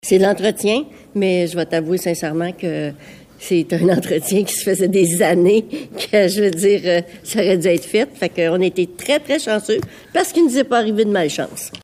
Tout d’abord, les six réservoirs d’eau chaude du Centre Jean-Guy-Prévost n’avaient pas été remplacés depuis plus de trente ans. La Municipalité a demandé des soumissions et les six réservoirs seront changés cet été, à la demande des assurances. La mairesse de Grand-Remous, Jocelyne Lyrette, commente :